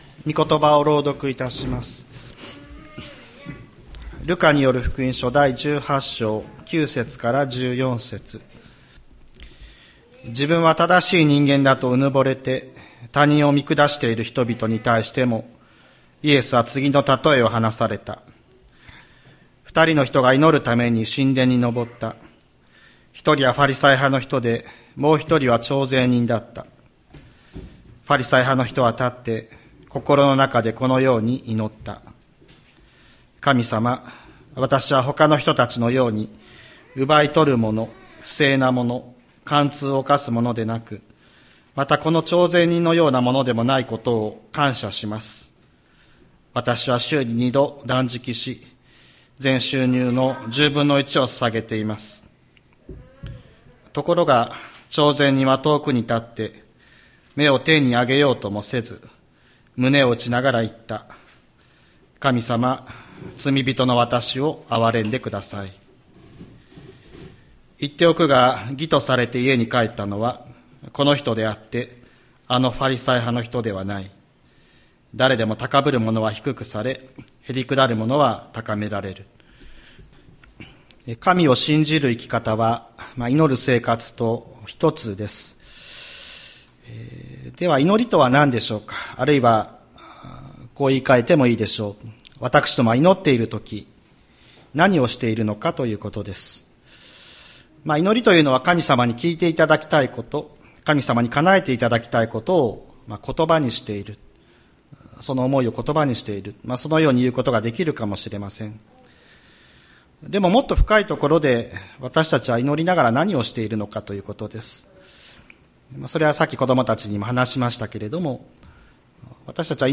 2023年07月23日朝の礼拝「まことの祈り」吹田市千里山のキリスト教会
千里山教会 2023年07月23日の礼拝メッセージ。